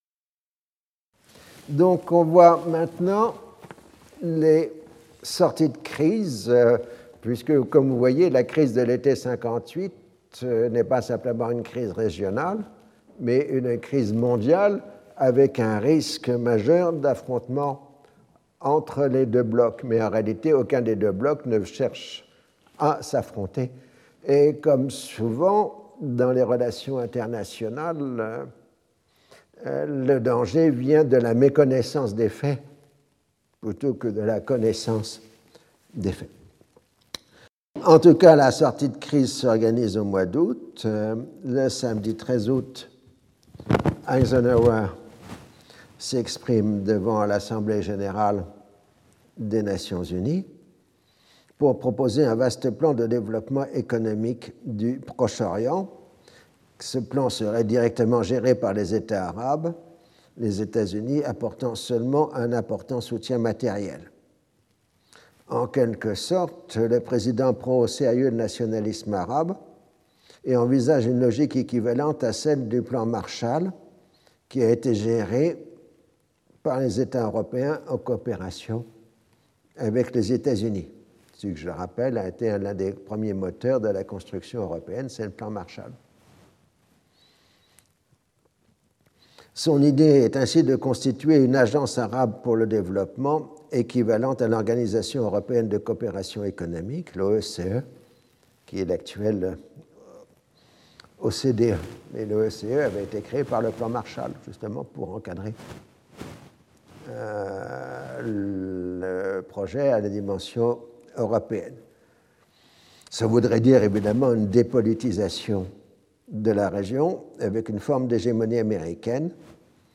Henry Laurens Professeur du Collège de France
Cours